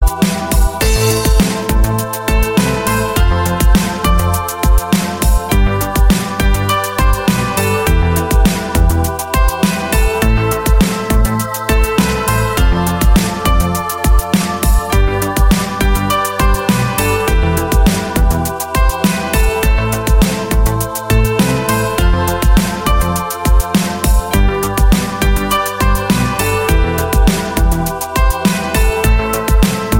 • Качество: 128, Stereo
мелодичные
без слов
90-е